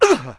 land01.ogg